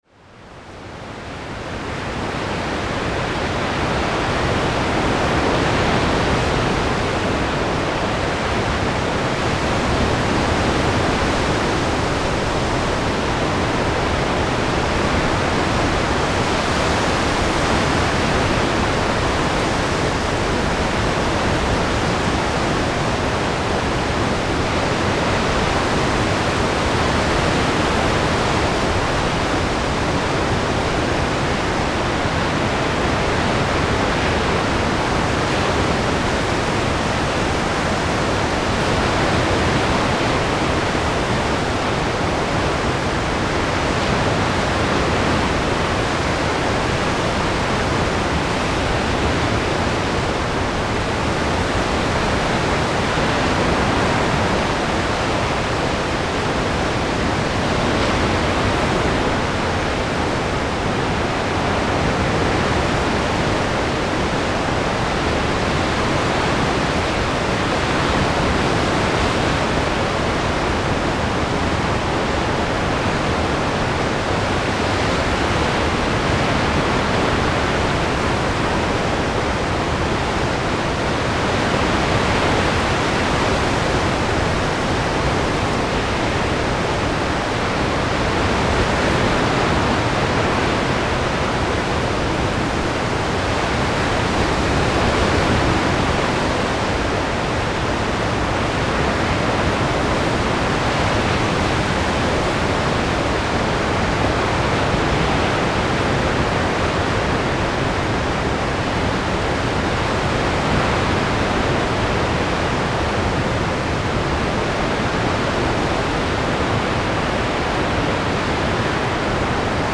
Location Study of Storm and Waves at the East-Coast from Germany 2007
Unprocessed Overground Recording with Sennheiser Mkh 416 P, Windshield and Marantz PMD 660.
Recorded in Neuhof, Island of Poel, East-Germany in Autumn 2007